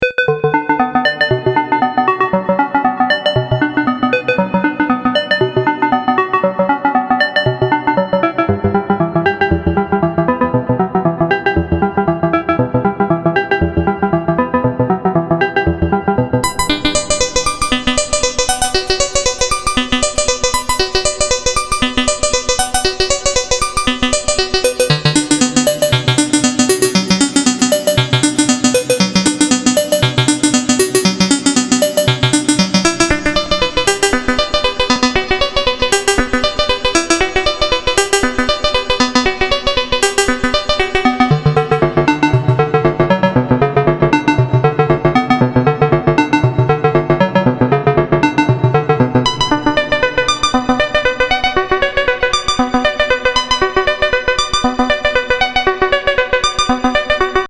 not a bad kind of clear sound, isn’t it?..